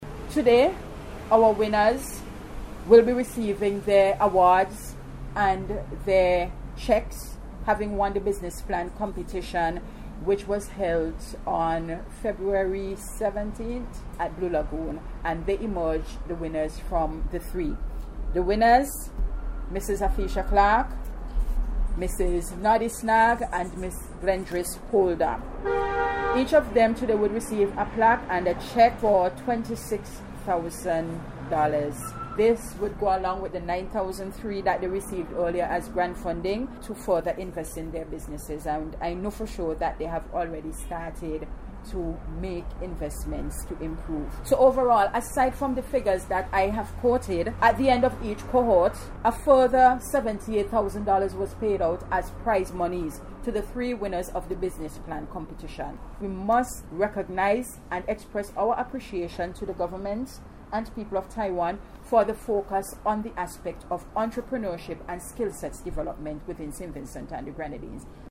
The three Winners of the Women’s Empowerment Project (WEP) Business Plan Competition received their awards and a sum of EC$ 26,000 each at a prize giving ceremony this morning at the Embassy of the Republic (China) Taiwan.